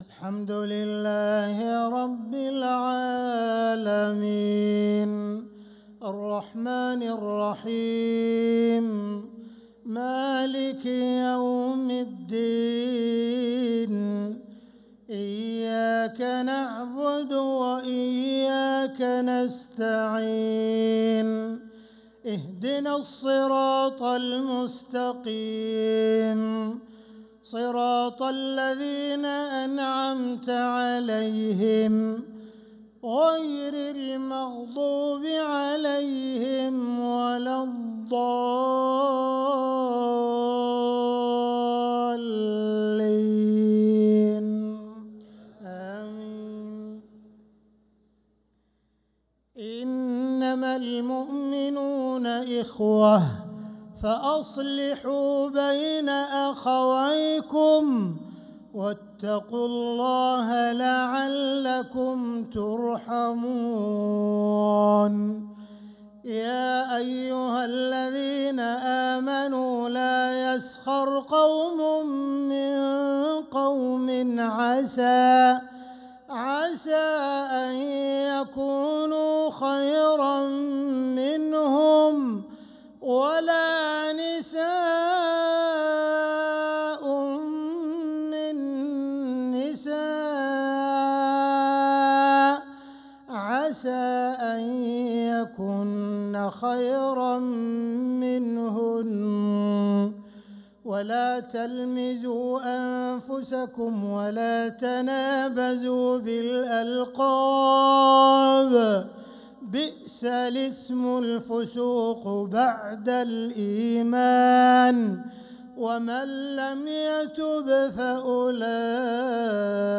مغرب الخميس 6-9-1446هـ من سورة الحجرات 10-13 | Maghrib prayer from Surat al-Hujurat 6-3-2025 > 1446 🕋 > الفروض - تلاوات الحرمين